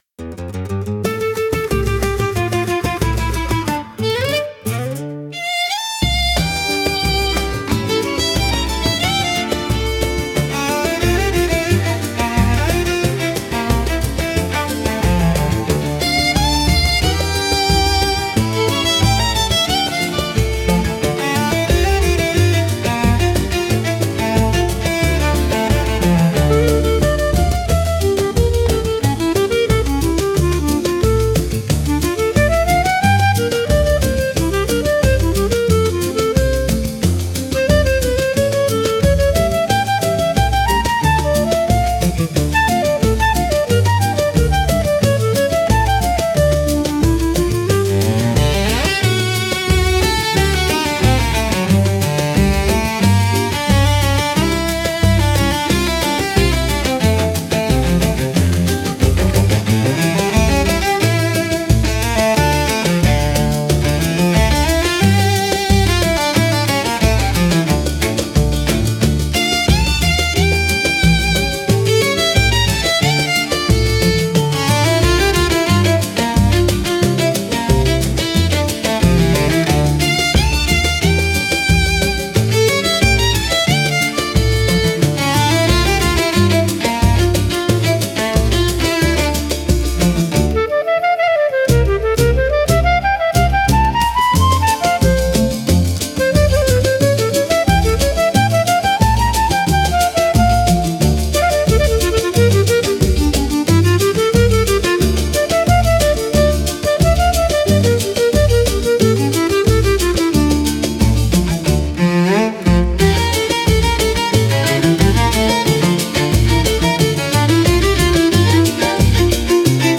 musica, arranjo e voz: IA